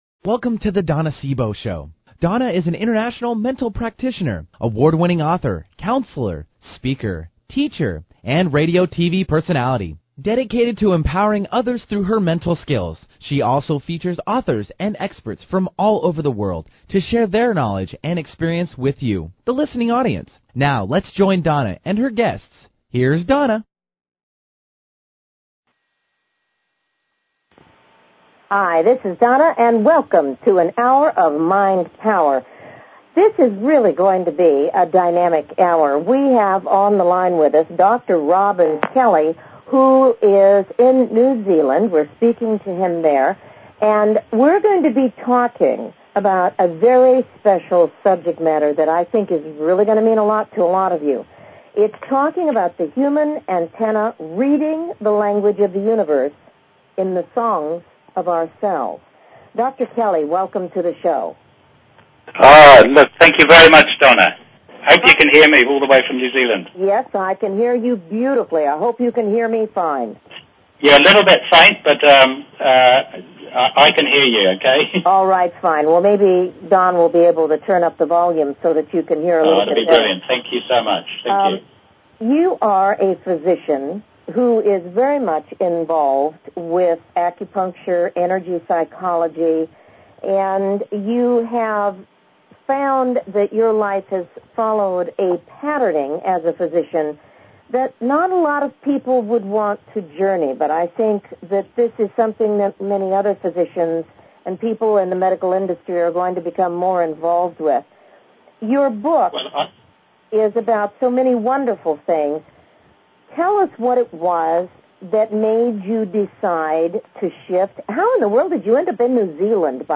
Talk Show Episode, Audio Podcast
We'll be traveling to New Zealand for this interview. We humans have some fascinating talents that are finally being recognized by science.